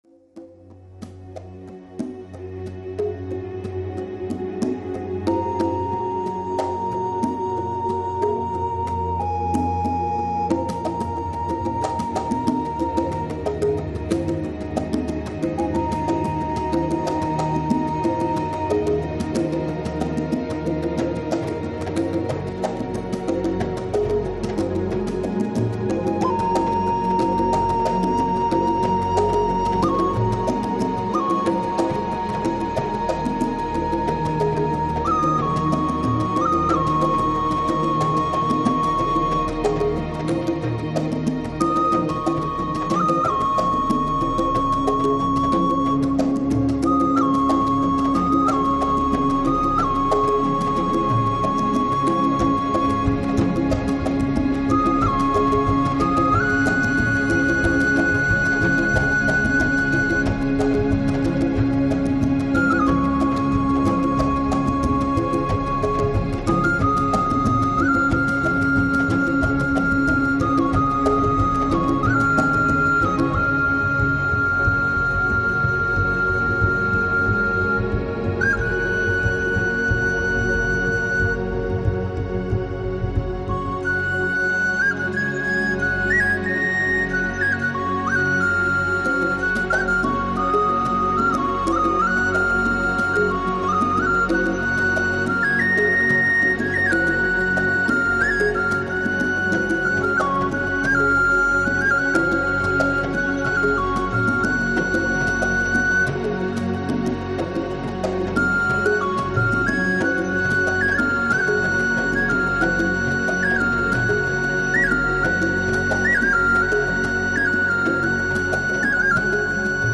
Genre: New Age